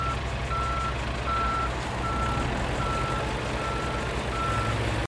Camion de travaux
07.chasse_tresor_lutins_Camion-de-travaux.mp3